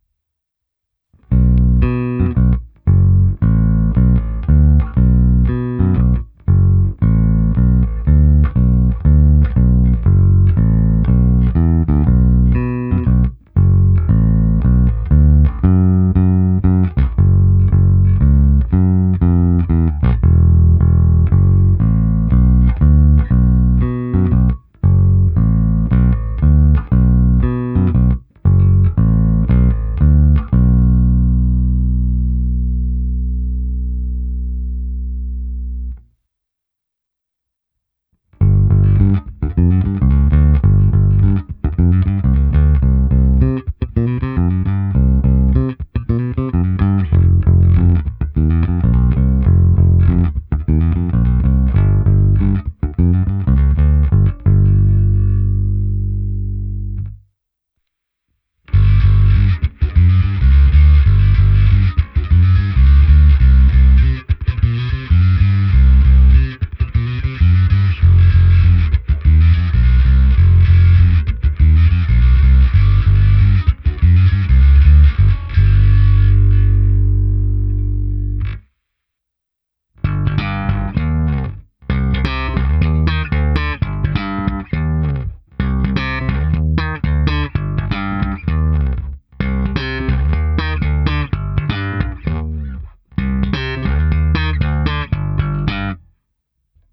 Abych simuloval, jak hraje baskytara přes aparát, protáhnul jsem ji preampem Darkglass Harmonic Booster, kompresorem TC Electronic SpectraComp a preampem se simulací aparátu a se zkreslením Darkglass Microtubes X Ultra. V nahrávce jsem použil i to zkreslení a také hru slapem.
Ukázka přes "aparát"